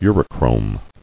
[u·ro·chrome]